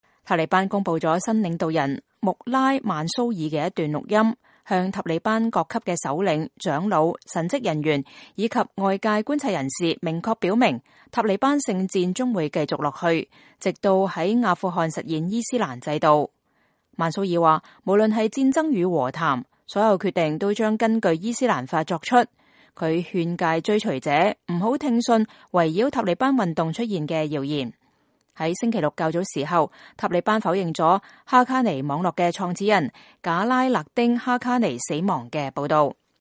塔利班公佈了新領導人穆拉曼蘇爾的一段錄音，向塔利班各級首領、長老、神職人員以及外界觀察人士明確表明，塔利班聖戰將繼續下去，直到在阿富汗實現伊斯蘭制度。曼蘇爾說，無論是戰爭與和談，所有決定都將根據伊斯蘭法做出。